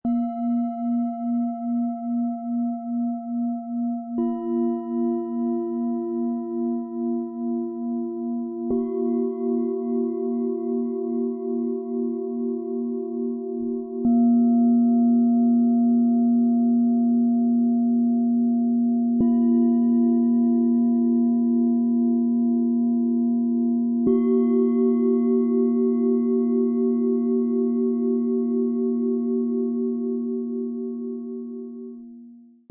Zentriert im Jetzt - Klarheit für Herz und Kopf - Set aus 3 Klangschalen, Ø 12,5 - 14,8 cm, 1,25 kg
Herz- und Universal-Schale. Klingt warm und harmonisch. Die Schwingung breitet sich sanft aus und unterstützt innere Sammlung.
Obere Herz- und Halsschale. Klingt klar und gut wahrnehmbar. Die Schwingung wirkt ordnend und gibt Struktur.
Kopfschale. Klingt sehr hoch und funkelnd. Regt Fokus und kreative Impulse an.
Im Sound-Player - Jetzt reinhören lässt sich der Original-Klang der drei Schalen direkt anhören – vom sammelnden Herzton bis zur klaren Kopfbrillanz.
Mit dem beiliegenden Klöppel erklingen die Schalen klar und ausgewogen.